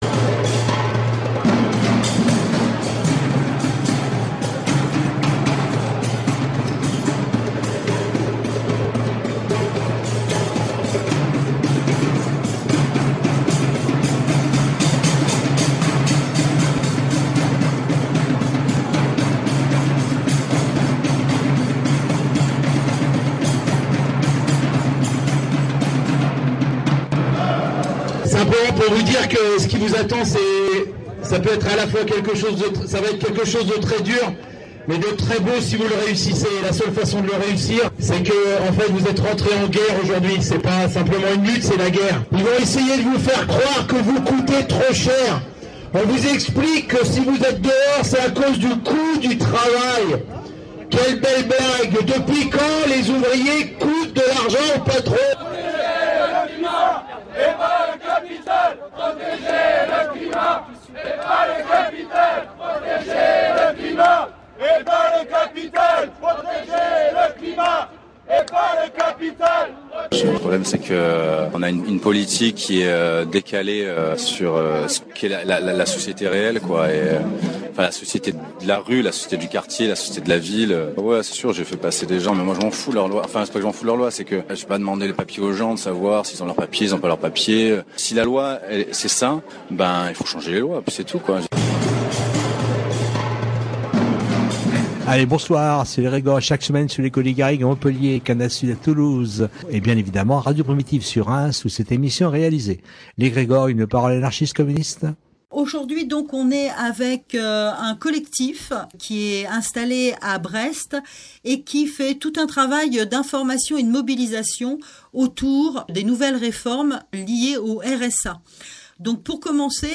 Aujourd’hui vous pouvez entendre une interview réalisée avec le collectif autonome de Brest sur le travail qu’il fait sur le RSA. A la fois un travail d’information sur la réalité de la nouvelle réforme mais aussi un travail de mobilisation. classé dans : société Derniers podcasts Découvrez le Conservatoire à rayonnement régional de Reims autrement !